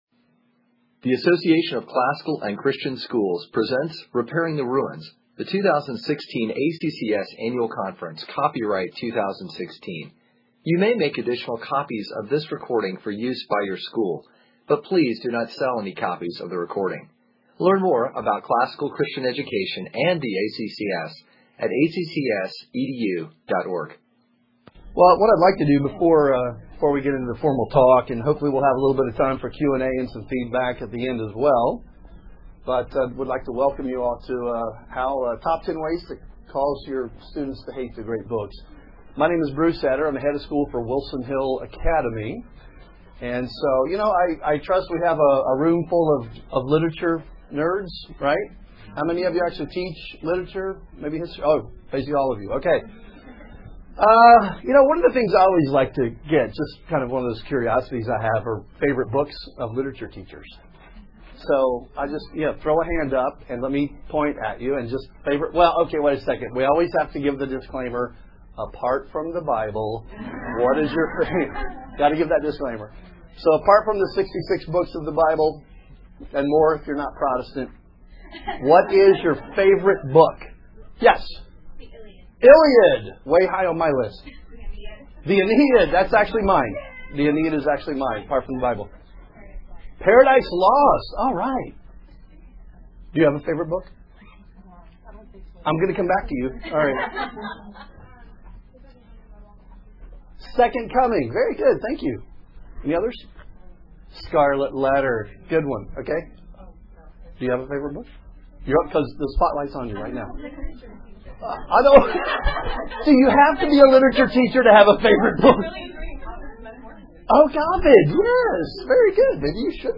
2016 Workshop Talk | 1:03:40 | All Grade Levels, Literature
Additional Materials The Association of Classical & Christian Schools presents Repairing the Ruins, the ACCS annual conference, copyright ACCS.